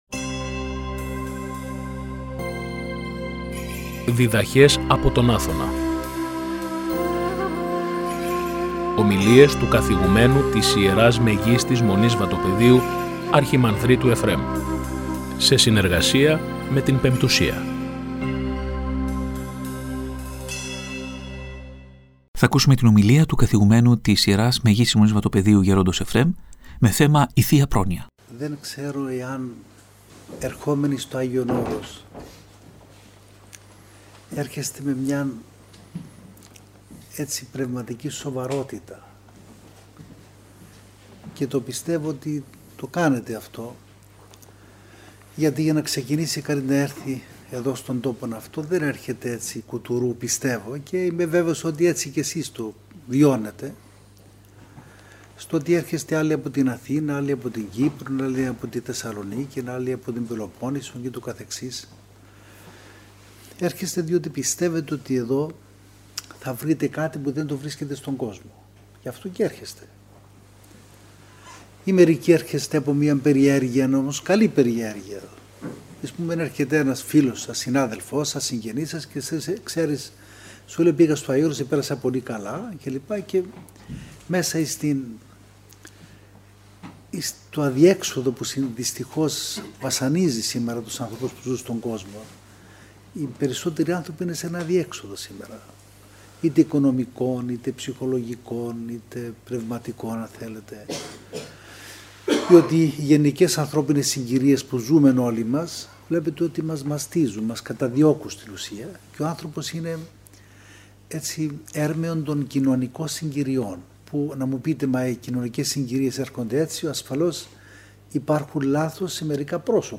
Ομιλία
Η ομιλία μεταδόθηκε και από την εκπομπή «Διδαχές από τον Άθωνα» στη συχνότητα του Ραδιοφωνικού Σταθμού της Πειραϊκής Εκκλησίας την Κυριακή 25 Ιουνίου 2023.